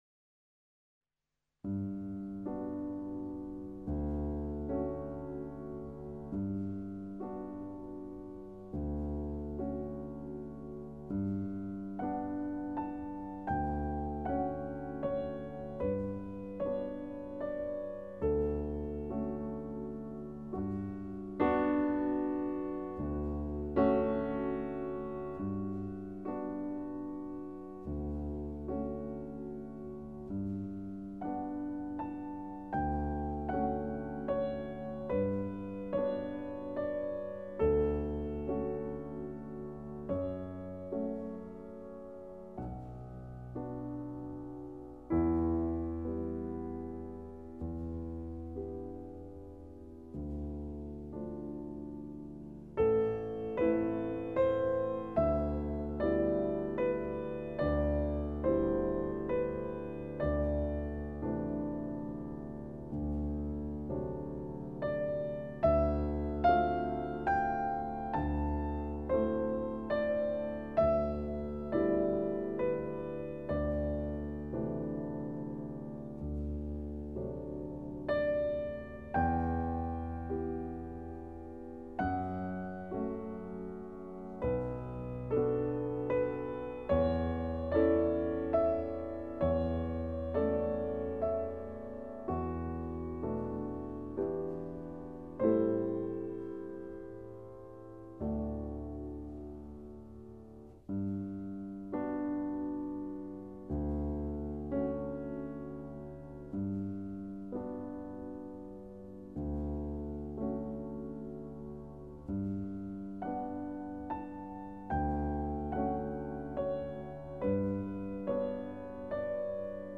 (Lent et douloureux)" by Erik Satie.